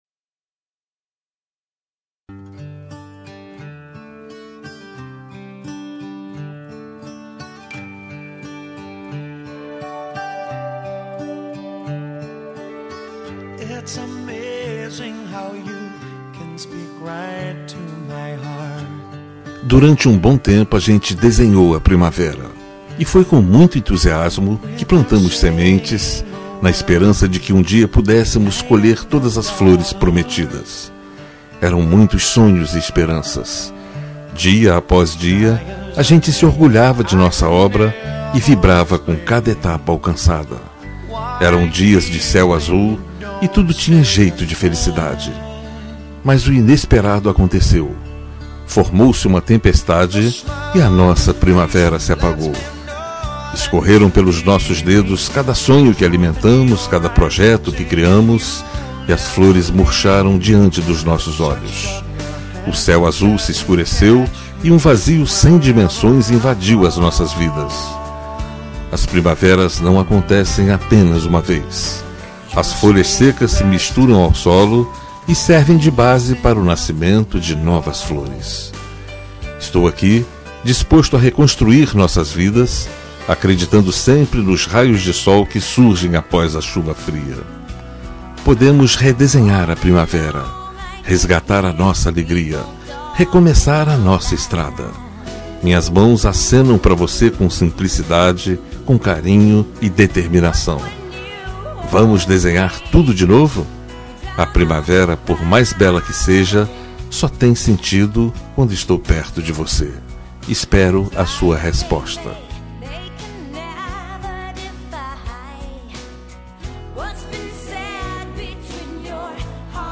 Telemensagem de Reconciliação Romântica – Voz Masculina – Cód: 950
Vamos resgatar nossa alegria masc 950.mp3